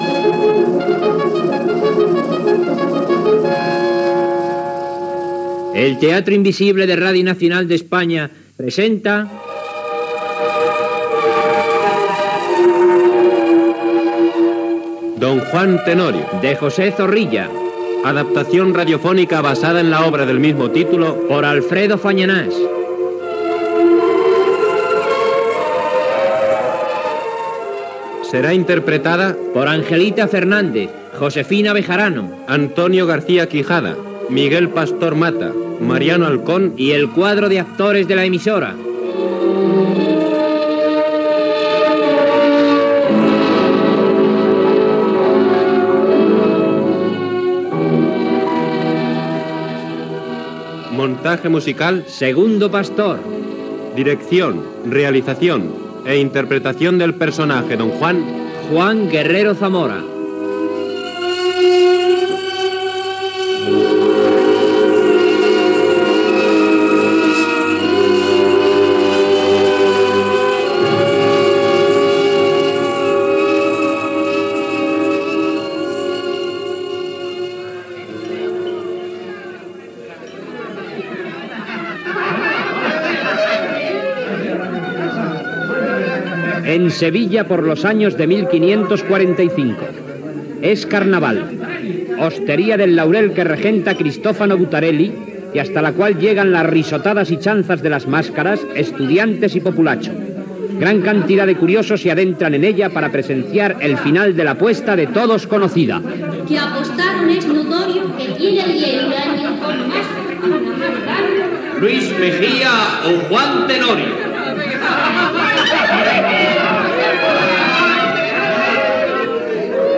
Careta del programa, amb el repartiment i l'equip.
Gènere radiofònic Ficció